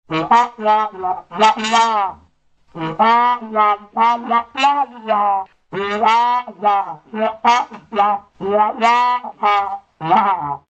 Charlie Brown Wah Wah Wah Sound Effect Free Download
Charlie Brown Wah Wah Wah